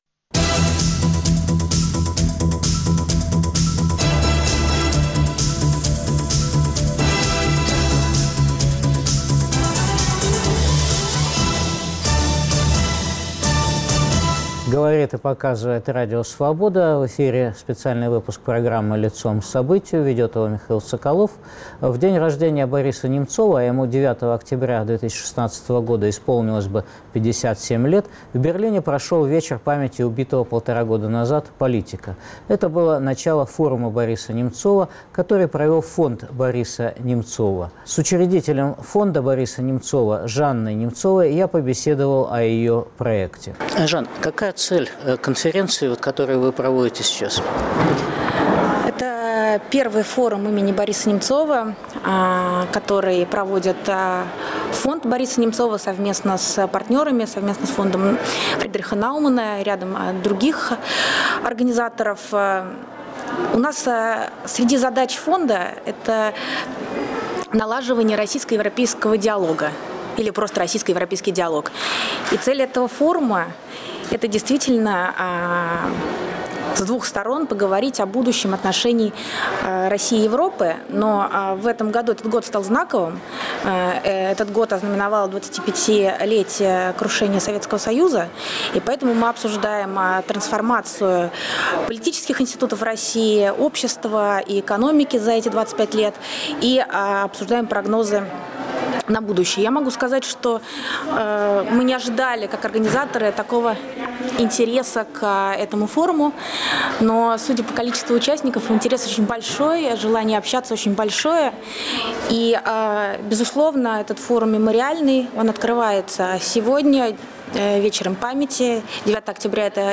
Интервью и выступления на Форуме Бориса Немцова